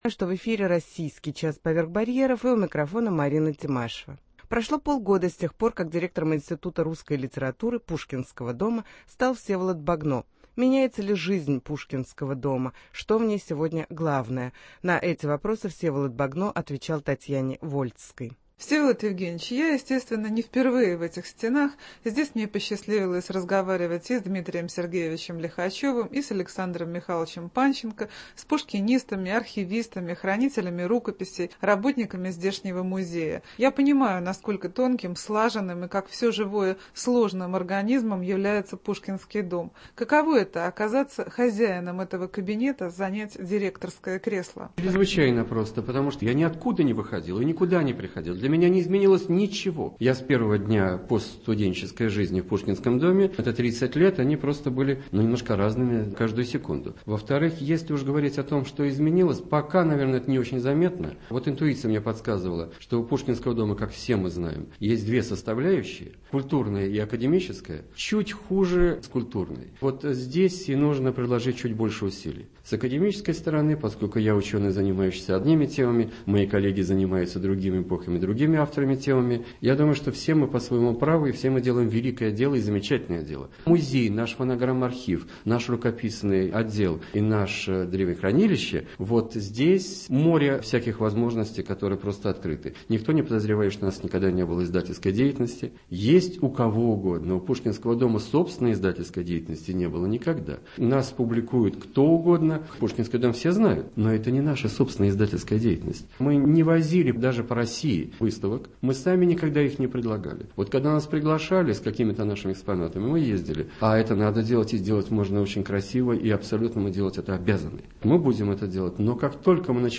Пушкинский дом. Интервью